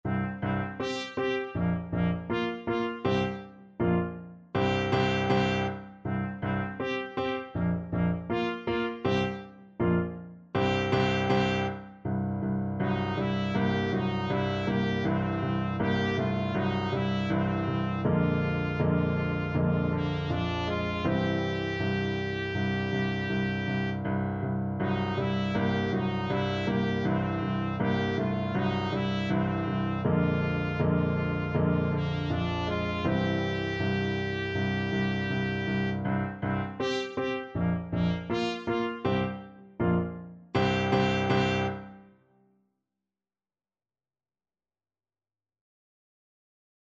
4/4 (View more 4/4 Music)
Trumpet  (View more Easy Trumpet Music)
Jazz (View more Jazz Trumpet Music)